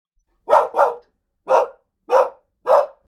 Dog Barking Twice - Short And Clear (demo) Sound Button - Free Download & Play